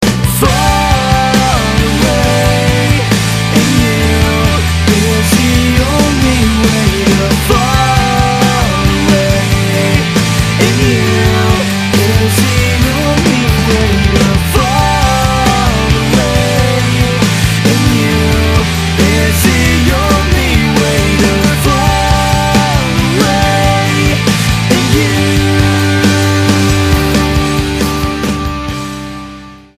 STYLE: Rock
A confident sound and expensive production sheen